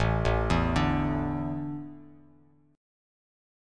Asus4 Chord